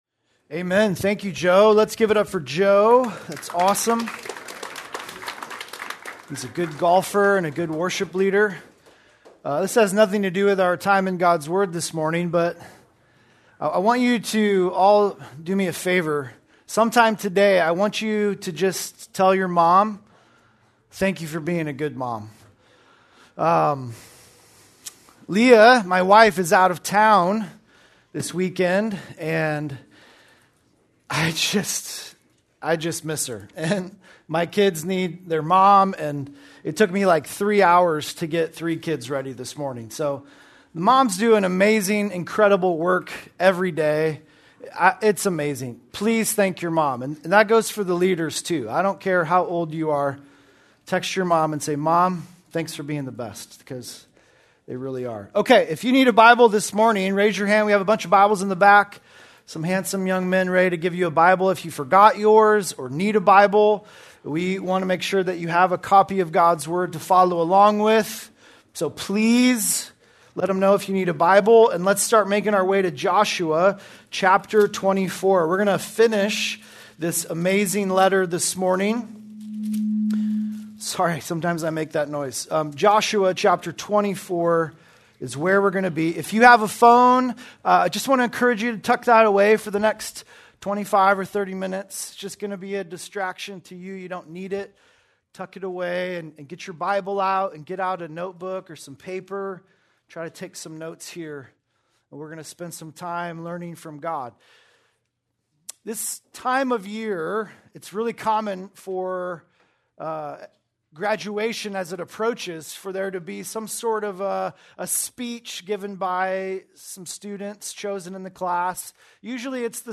April 19, 2026 - Sermon | Xchange | Grace Community Church